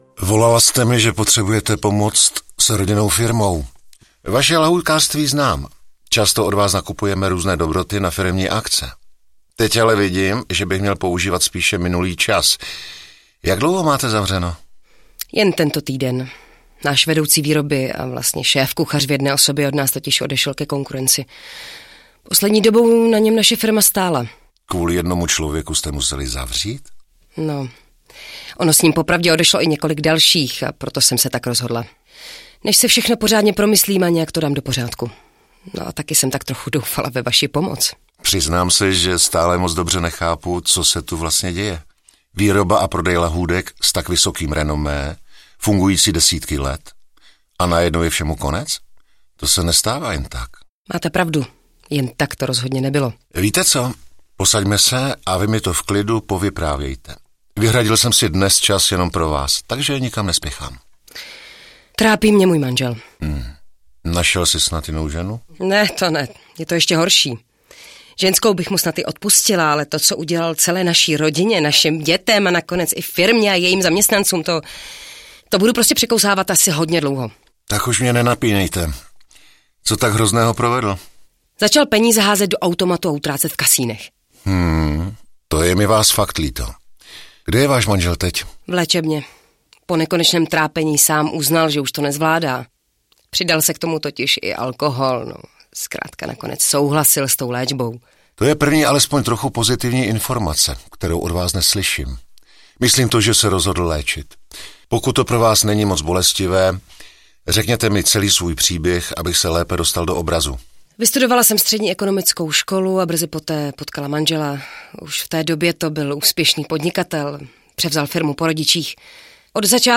Krotitel rizik podnikání zasahuje: Lahůdkářství audiokniha
Ukázka z knihy
• InterpretAlexej Pyško, Jitka Čvančarová